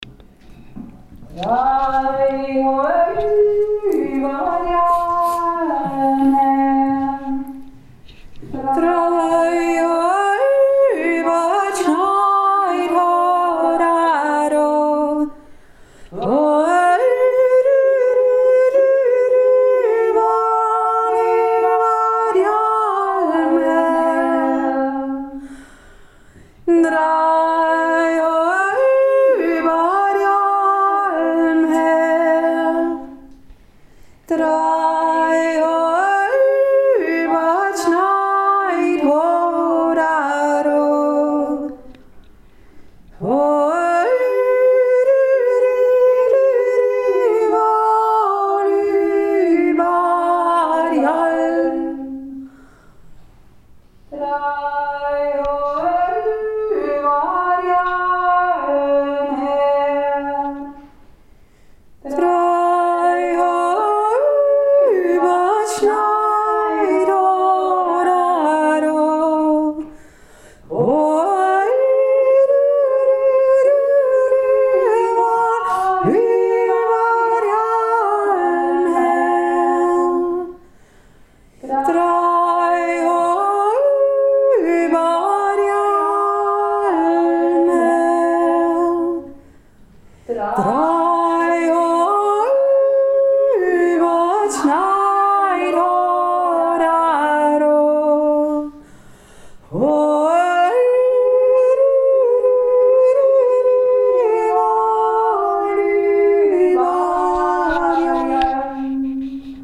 Goldegg jodelt März 2024
2. Stimme